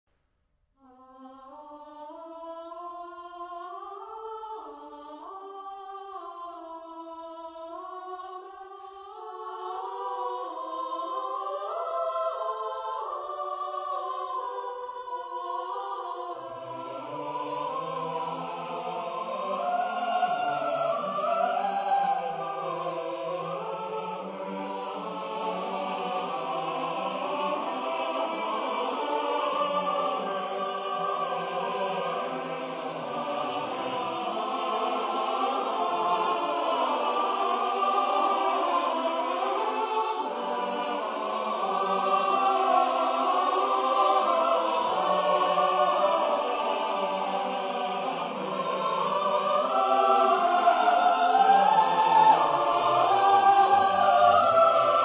Genre-Style-Form: Romantic ; Sacred
Mood of the piece: fugued
Type of Choir: SSATB  (5 mixed voices )
Tonality: C major